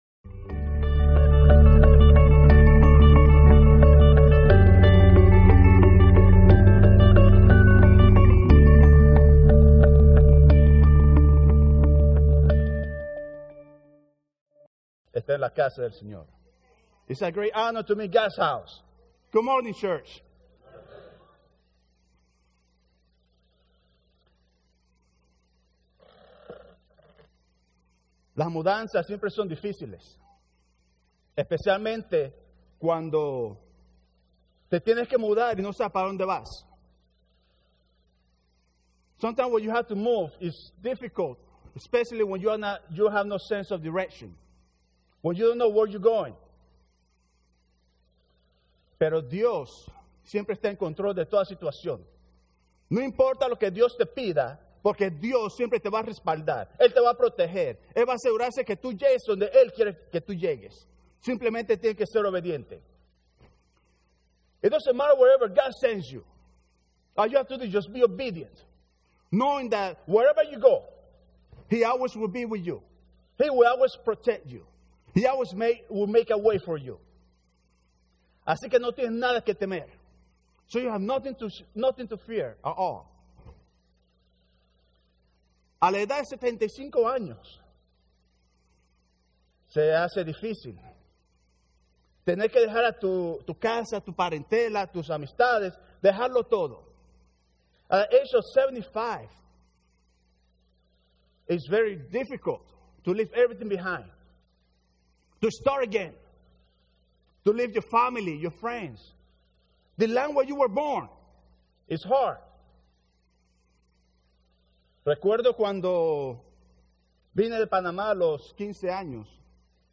When Promises Seem Impossible – Joint Service – Spanish & English Congregations - Phillipsburg Alliance Church